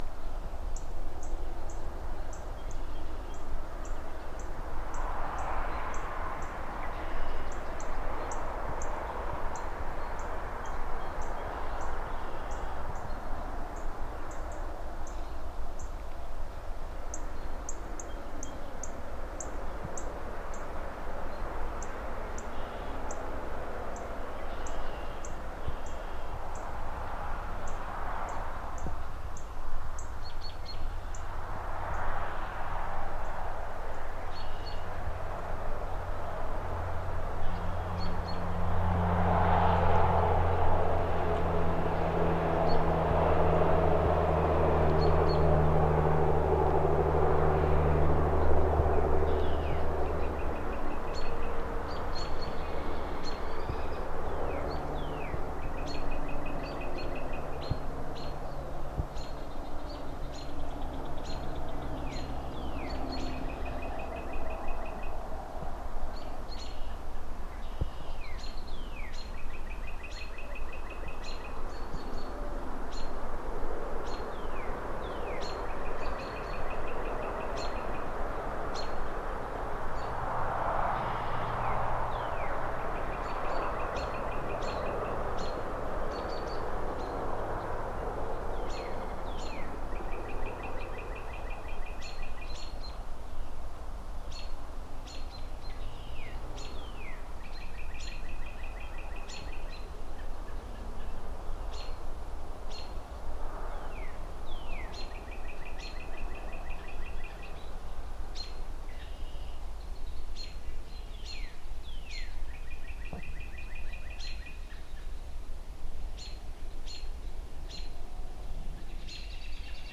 描述：水流入下水道，伴随着我伞上的水滴/lluvia en la alcantarilla y gotas en mi paraguas
标签： 氛围 双耳 城市 现场记录 性质 streetnoise
声道立体声